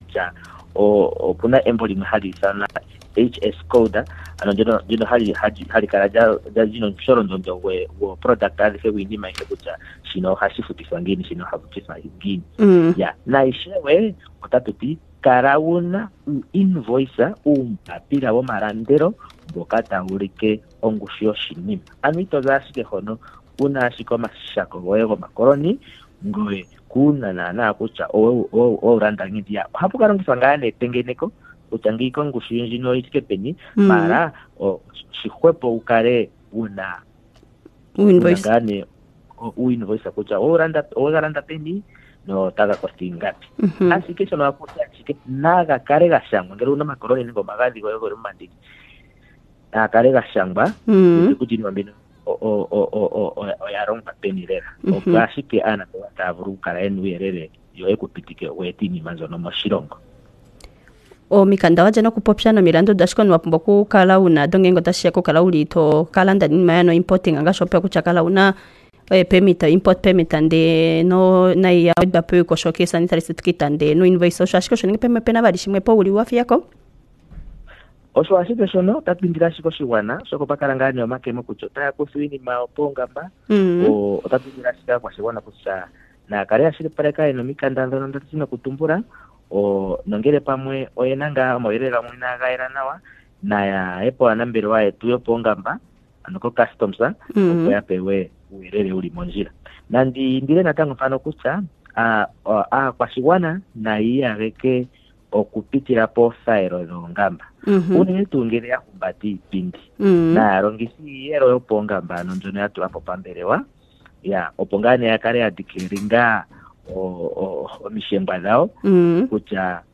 He also spoke about the necessary documents required to import goods. Click on the link below to listen to the interview.